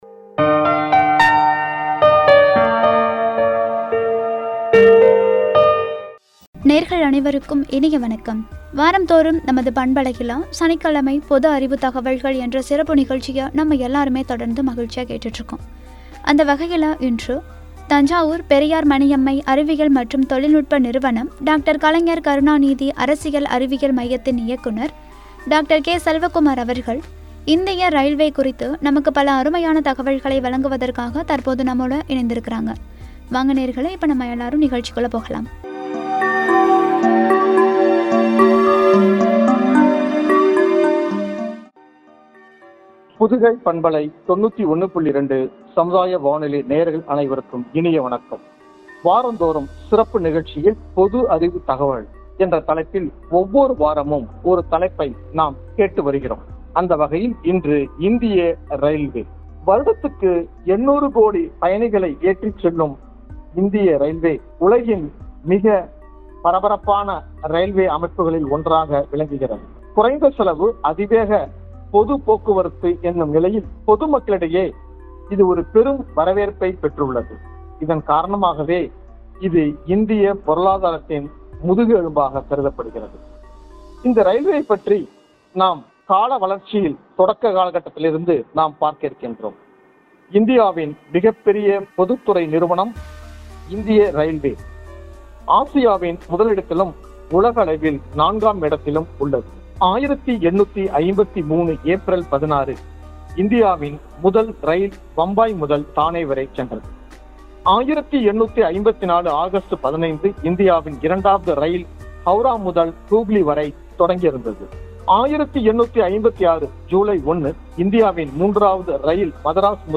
உரை